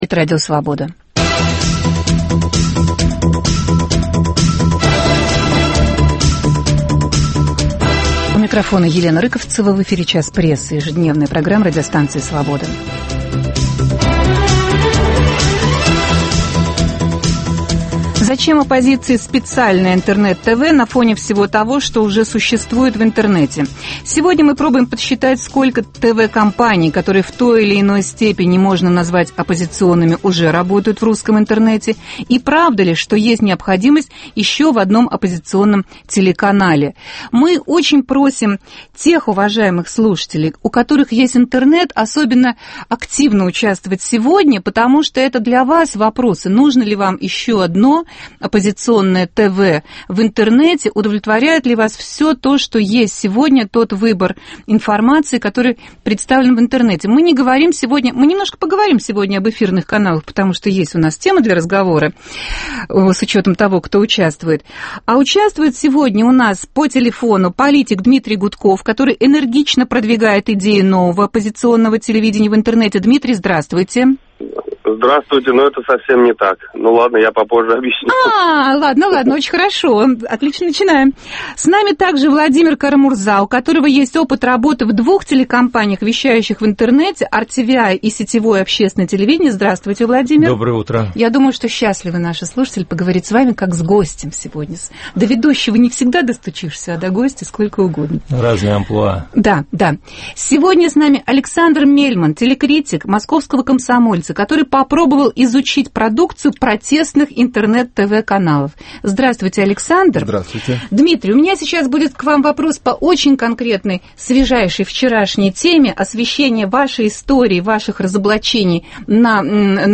Зачем оппозиции специальное ТВ? В прямом эфире пробуем подсчитать: сколько ТВ-компаний, которые в той или иной степени можно назвать оппозиционными, существует в русском интернете? Правда ли, что есть необходимость еще в одном оппозиционном ТВ?